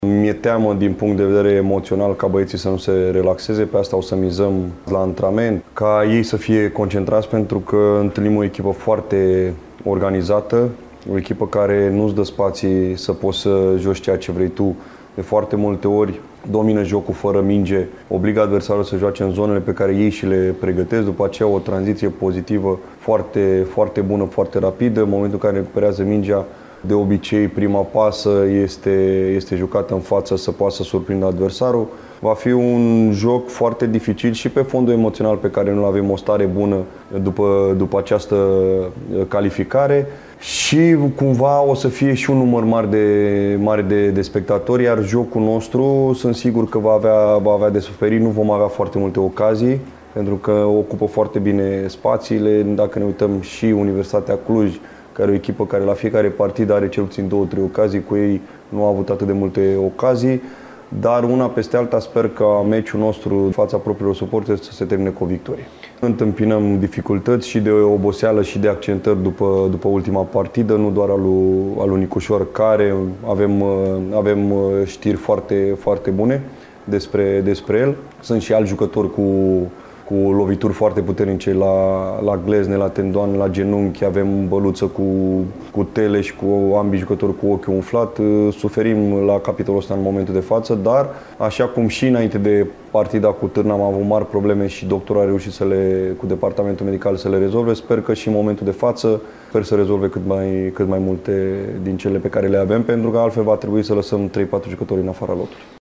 În conferința de presă premergătoare partidei, antrenorul Universității, Mirel Rădoi, a subliniat importanța celor trei puncte în lupta pentru fotoliul de lider și a cerut concentrare maximă din partea elevilor săi.